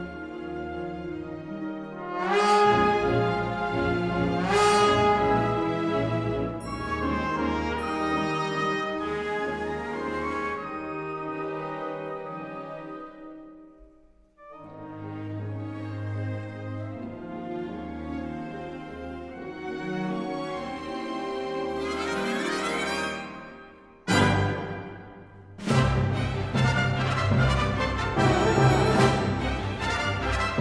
Musique classique - Musique orchestrale